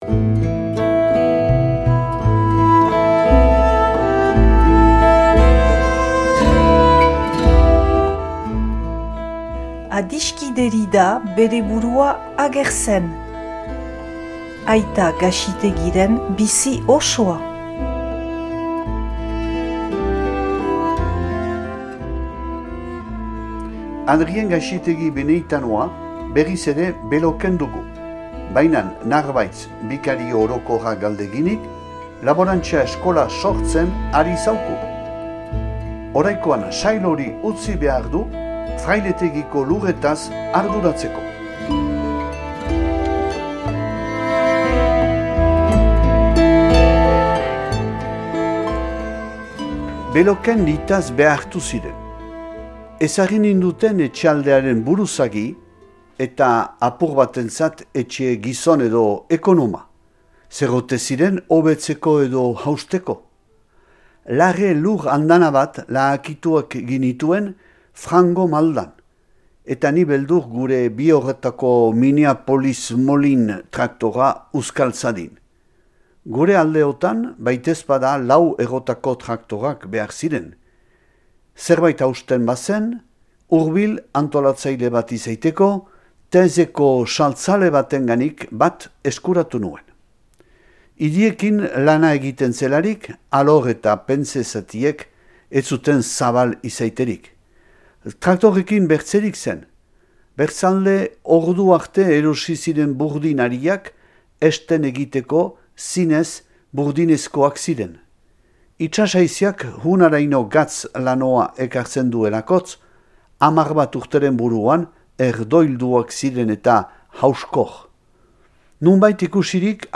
irakurketa bat dauzuegu eskaintzen.